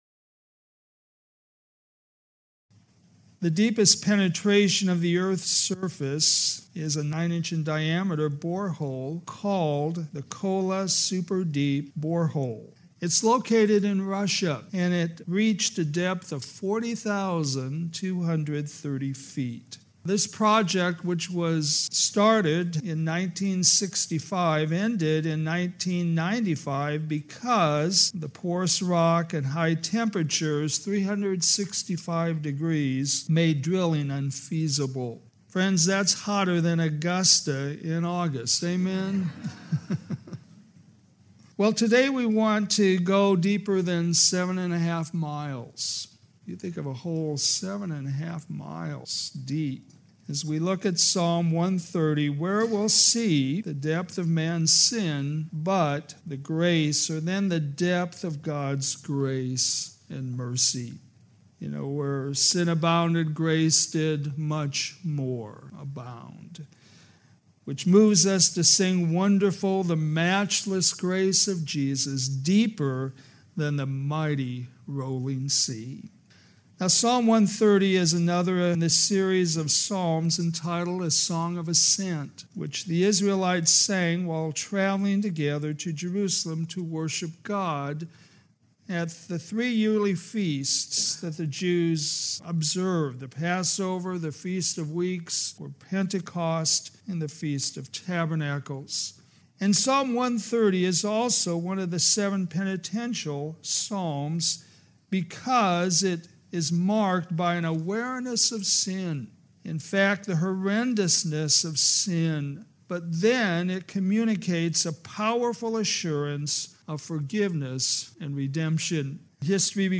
All sermons available in mp3 format